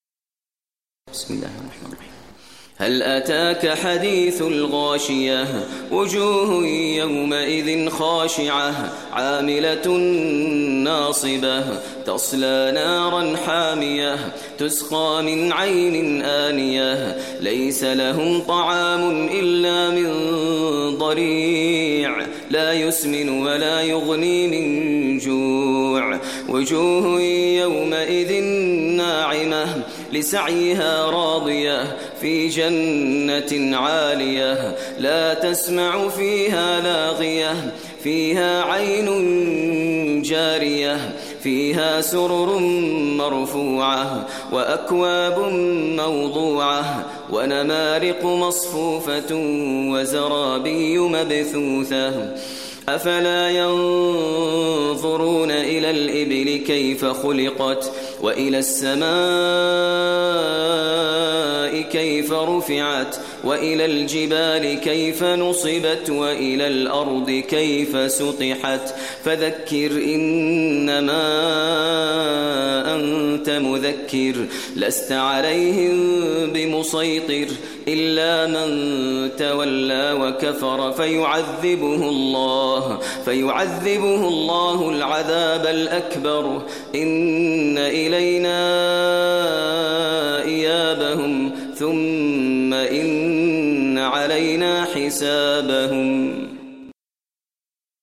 Surah Al-Ghashiyah, listen online mp3 tilawat / recitation in Arabic recited by Imam e Kaaba Sheikh Maher al Mueaqly.